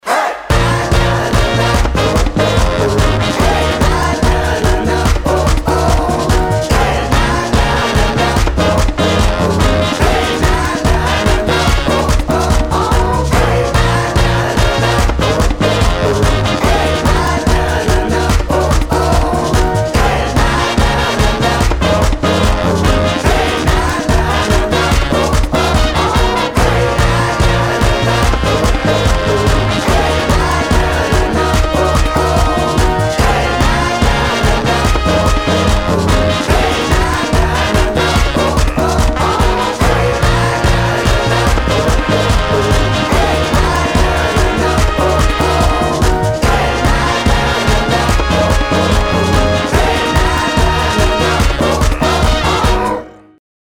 из Танцевальные